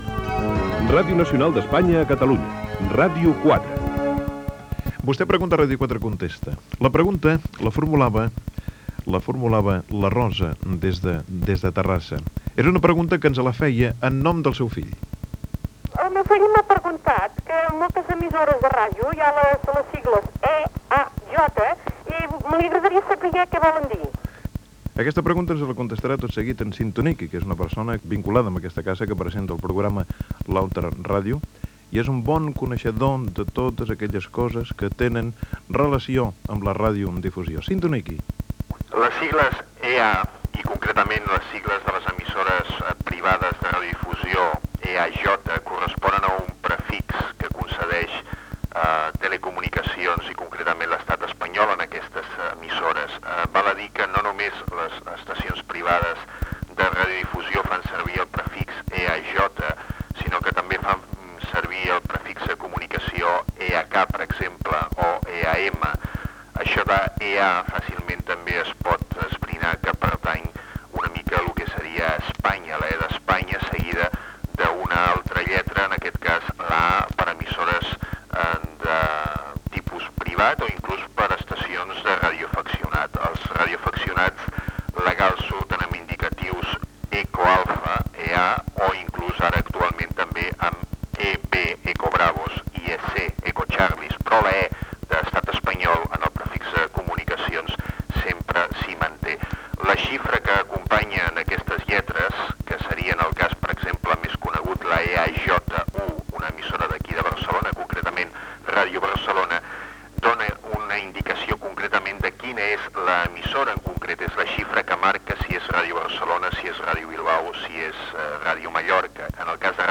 sobre el significat de les sigles EA. Gènere radiofònic Participació